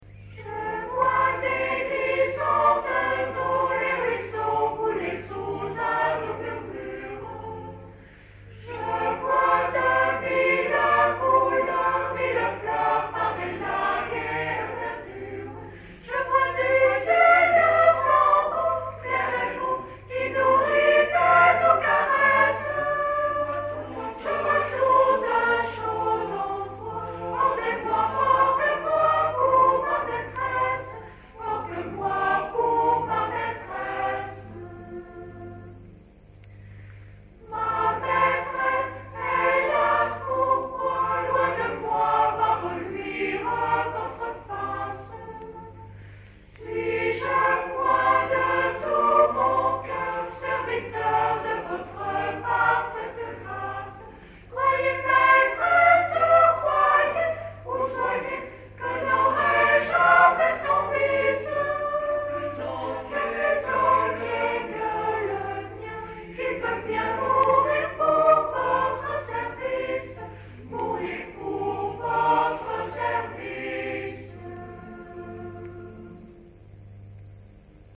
CHORALE RENAISSANCE de SAULXURES SUR MOSELOTTE